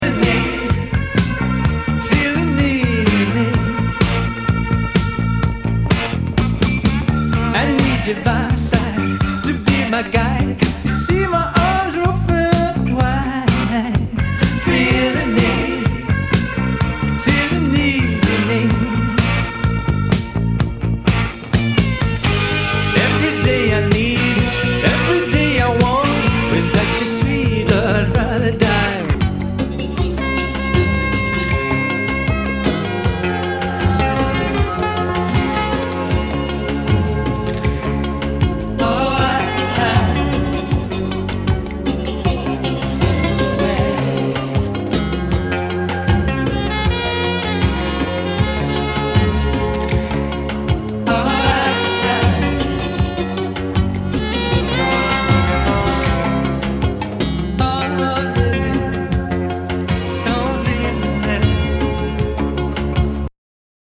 [ Genre ] ROCK/POPS/INDIE
シンセ・ポップ！